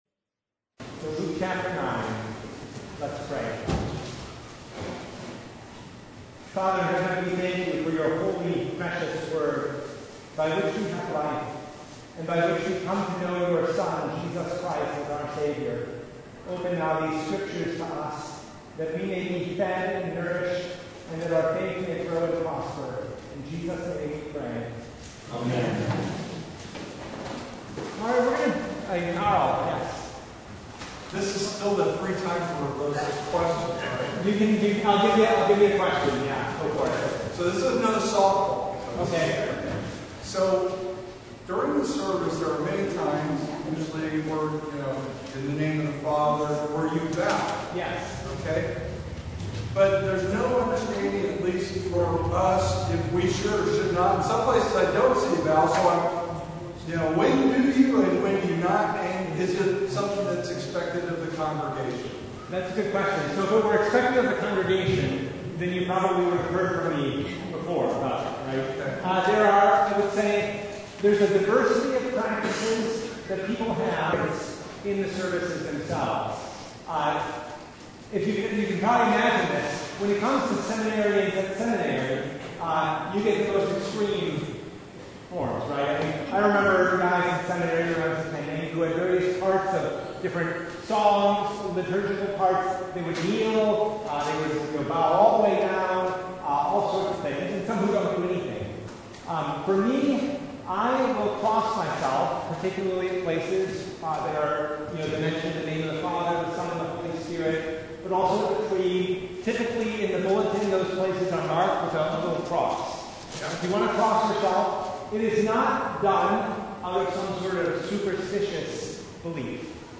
Service Type: Bible Study